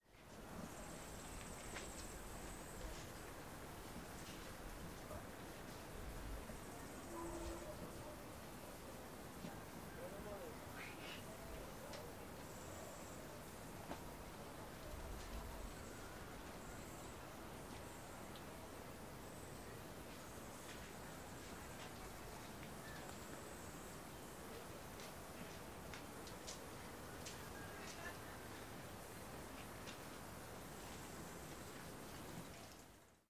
黑暗环境 " 黑暗环境003
描述：使用Audacity和Blue Cat PLugins制作
标签： 背景声 音场 氛围 环境 背景 ATMOS 黑暗 气氛
声道立体声